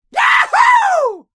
cheer3.ogg